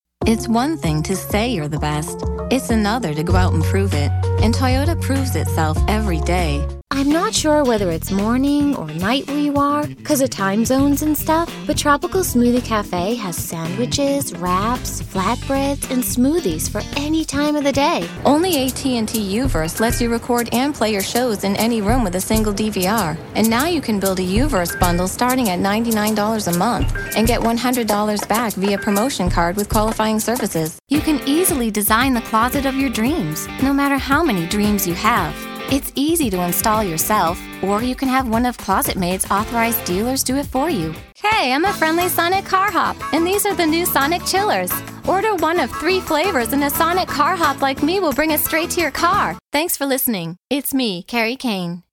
My voice has been described as friendly, youthful, wholesome, and real. But I can sound tough when I need to! Vocal age range teens-30s.
Sprechprobe: Werbung (Muttersprache):